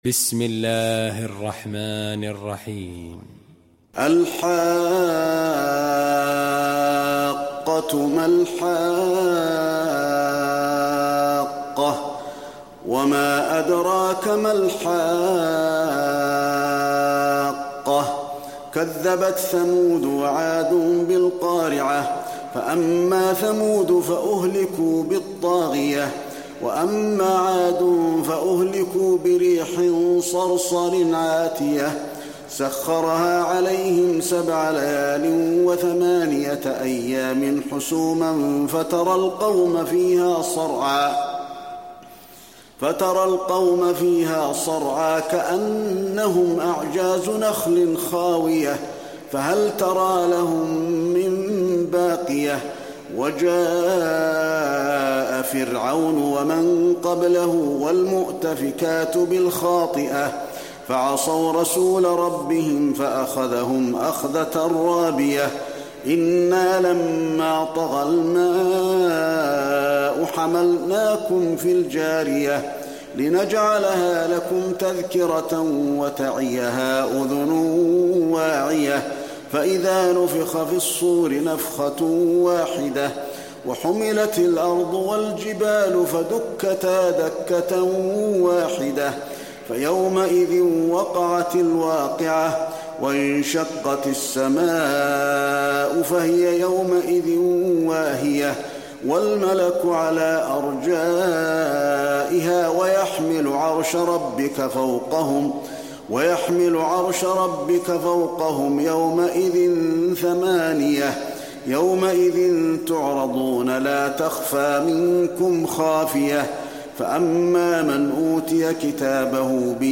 المكان: المسجد النبوي الحاقة